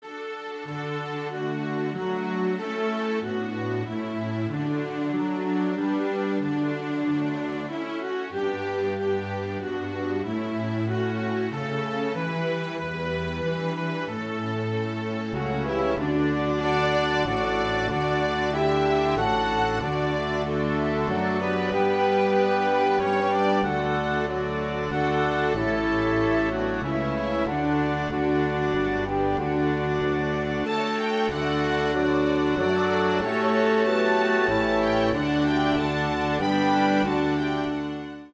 The three beautiful Northumbrian melodies